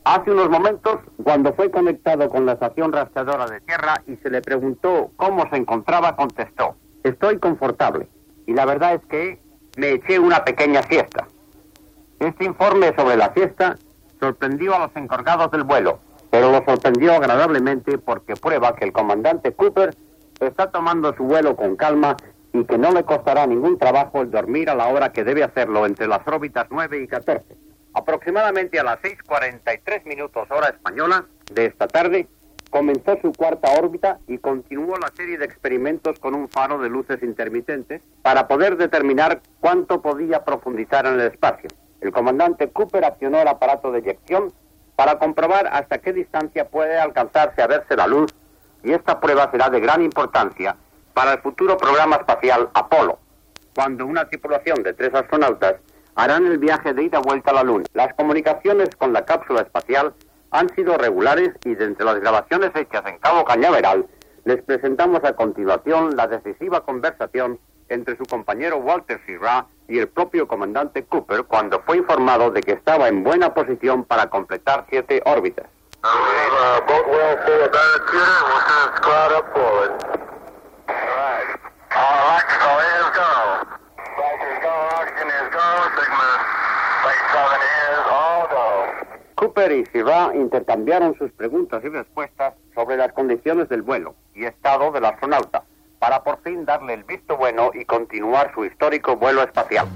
Informació des de Washington de la missió tripulada Mercury Atlas 9 dels EE.UU
Informatiu